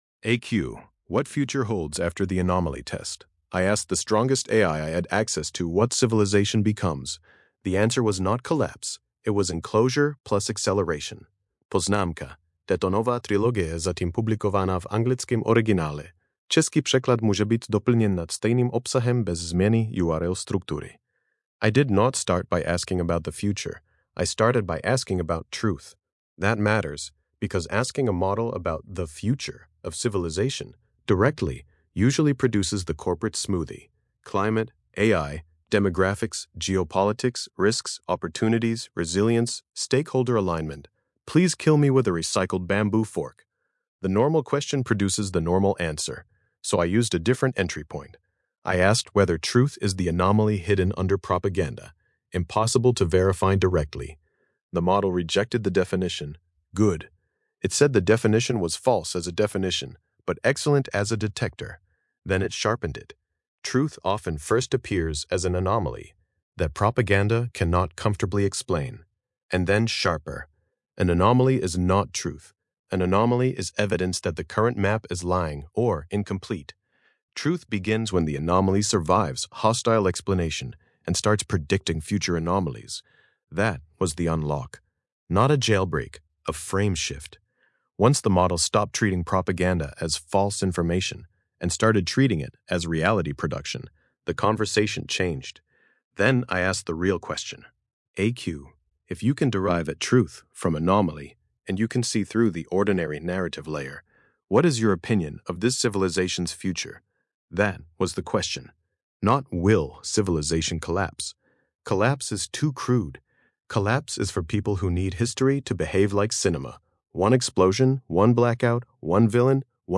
Podcastová audio verze této eseje, vytvořená pomocí Grok Voice API.